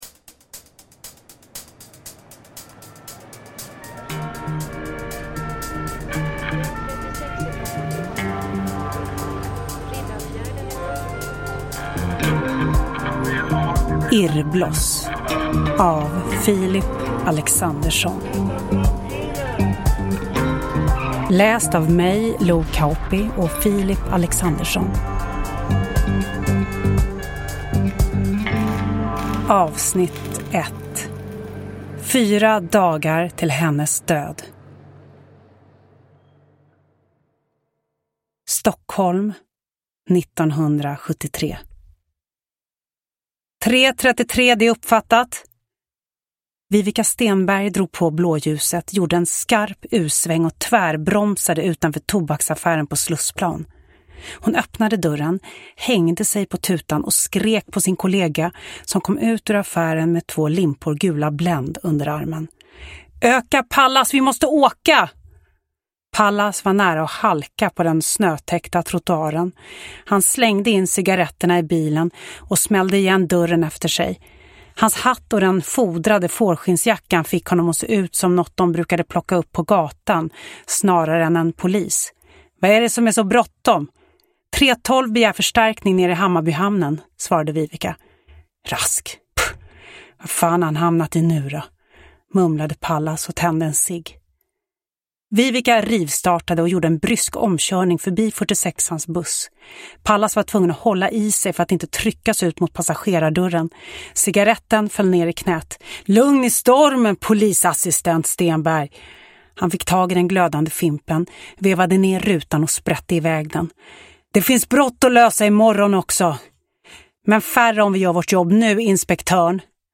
Hidden S1A1 Irrbloss : Fyra dagar till hennes död – Ljudbok – Laddas ner